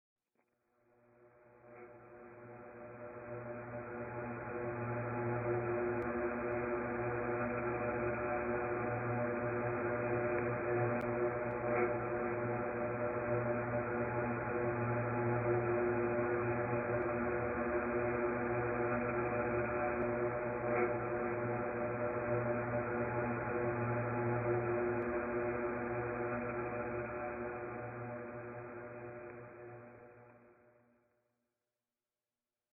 - real time sound tapped from inside didgeridoo
Recording of sound tapped from inside Gunter's exhaust pipe didge - the sound is conditioned by the physical specifics of the didge and is responsive to any incidental input from its surrounds: